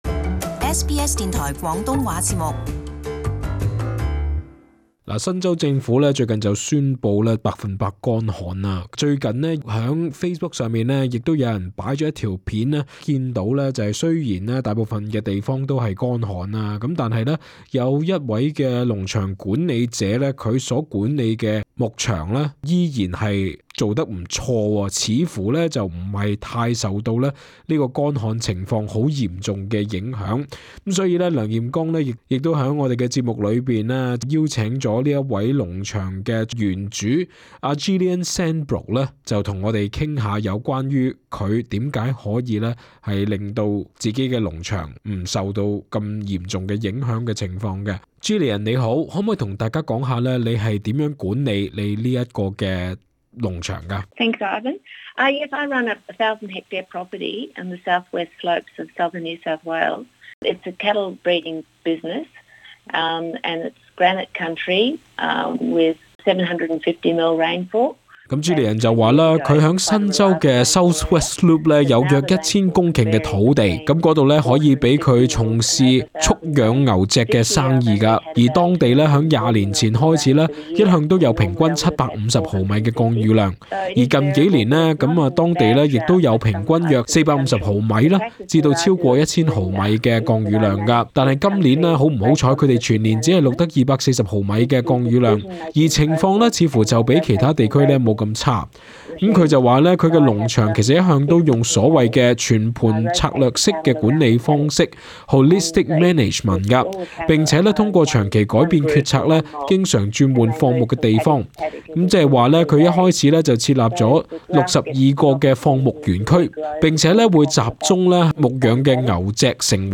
【特别访问】新州牧场如何对抗旱灾？